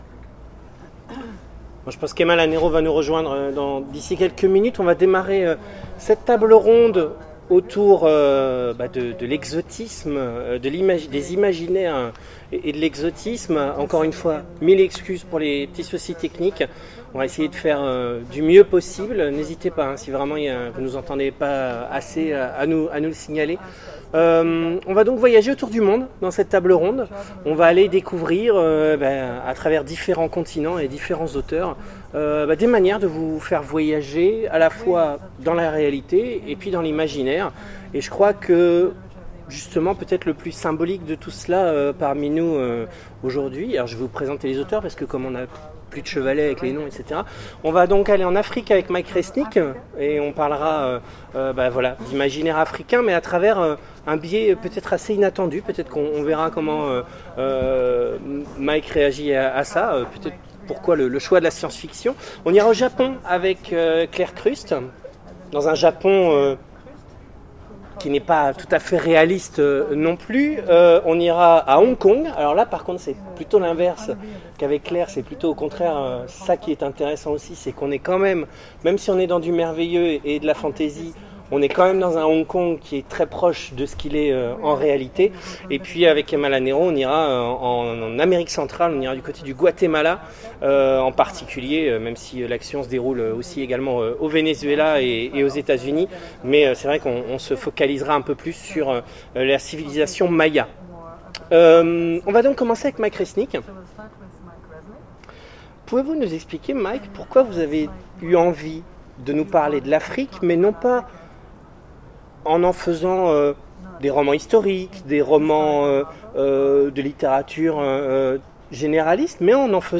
Imaginales 2016 : Conférence Afrique, Chine, Guatemala, Japon…
Imaginales_2016_conference_imaginaires_du_monde_ok.mp3